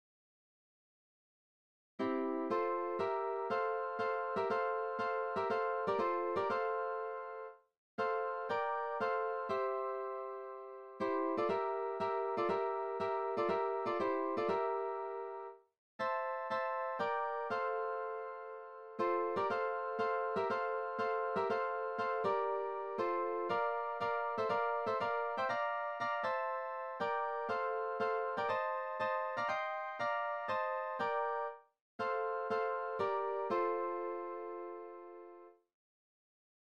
sound file for 3-part harmony above